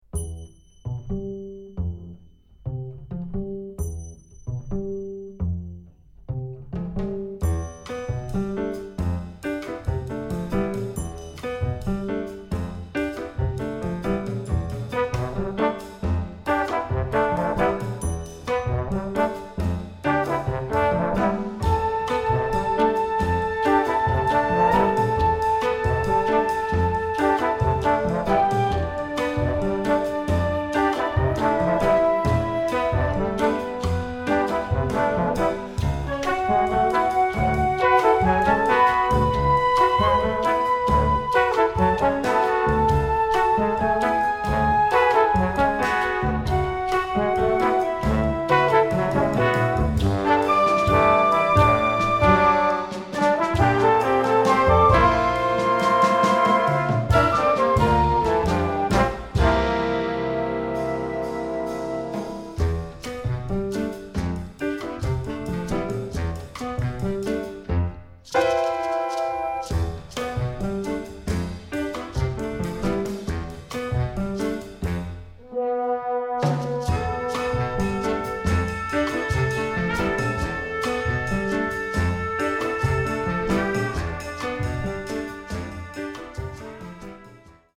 Doubles: flute, alto flute, clarinet, soprano, bass clarinet
Solos: piano